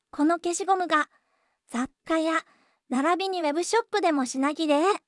voicevox-voice-corpus / ROHAN-corpus /WhiteCUL_たのしい /ROHAN4600_0034.wav